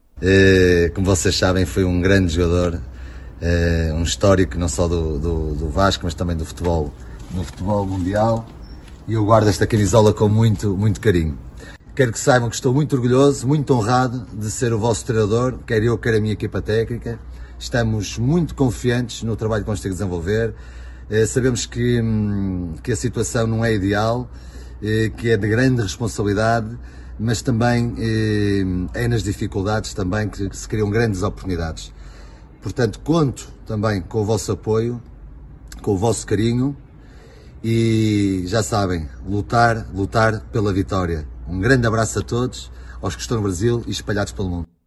Após ser anunciado pelo clube Ricardo Sá Pinto gravou um vídeo para as redes sociais com a camisa do Vasco assinada por Romário, para ele, referência mundial. O português falou do compromisso e o prazer de trabalhar no Brasil.